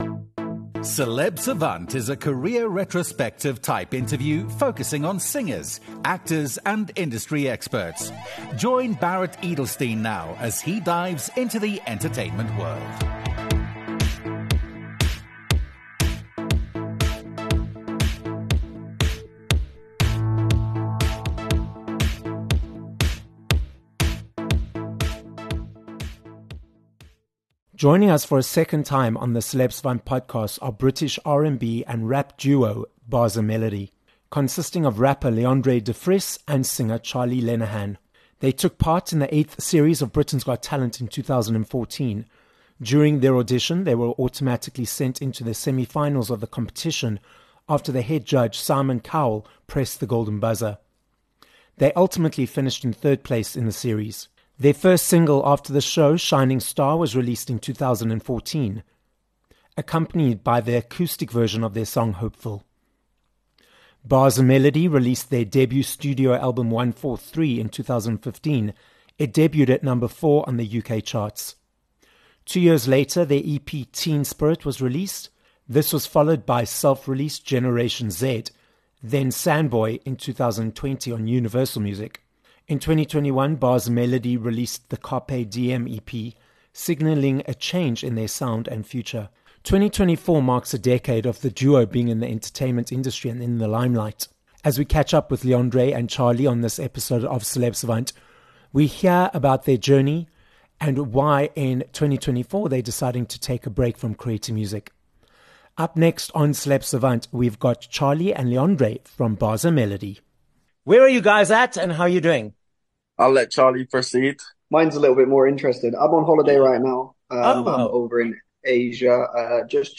Bars and Melody - the British R&B and rap duo consisting of Leondre Devries and Charlie Lenehan - join us for a second time on this episode of Celeb Savant. We catch up with the duo and hear about why, after a decade together, they have decided to part ways, their final world tour, and what's next for them.